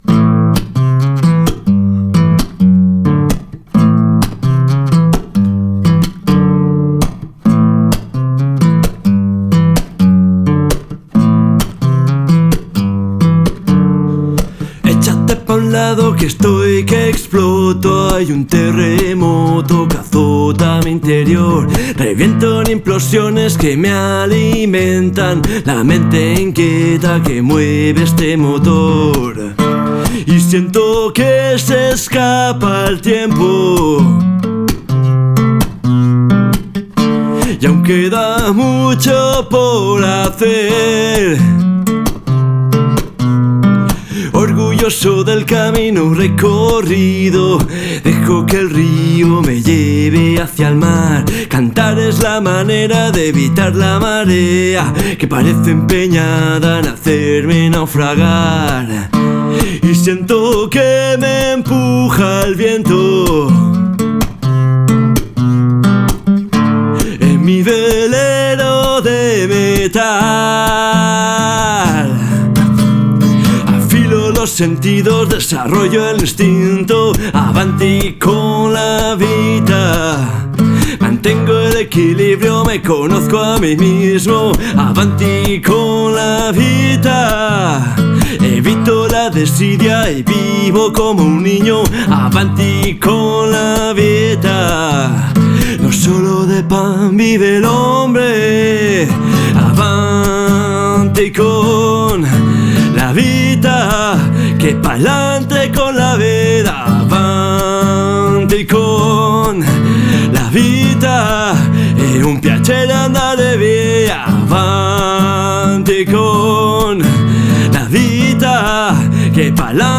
Proyecto de música del Mundo fusión.
World Music fusion project. https